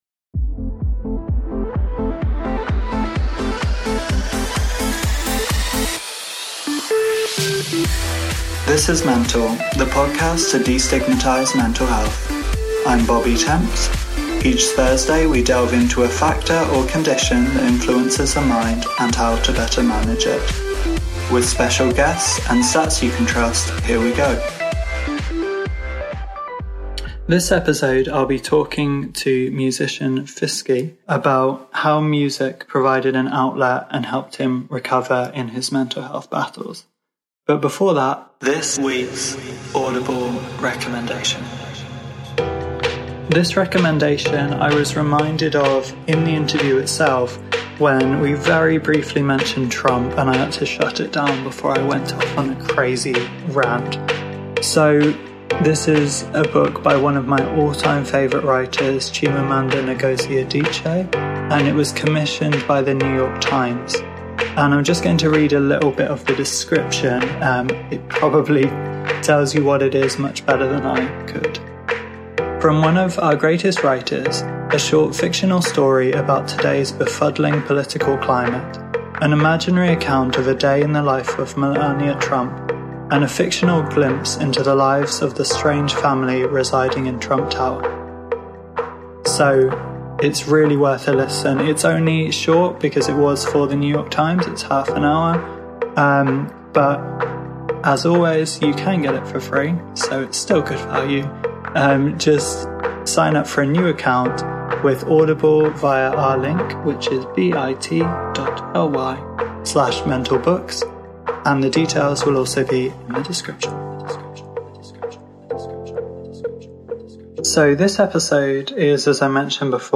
We also delve into addiction and grief in what makes for a fascinating conversation and one you won't soon forget.